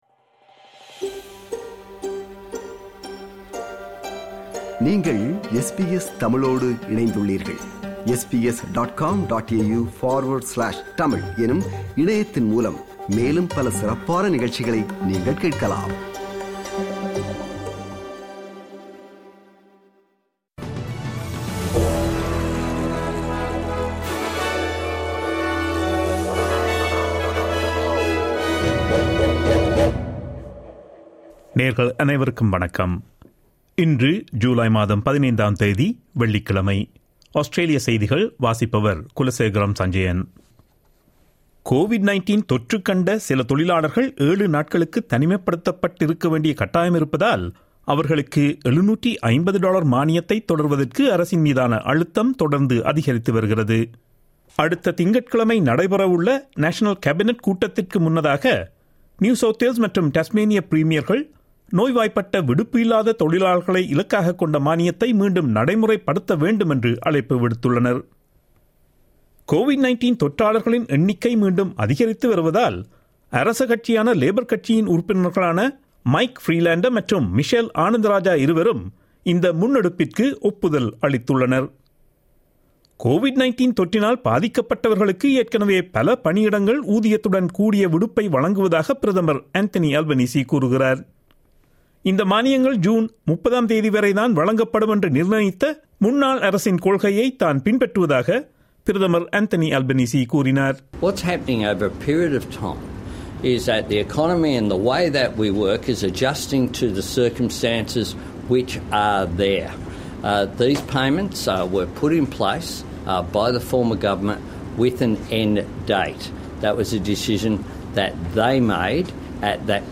Australian news bulletin for Friday 15 July 2022.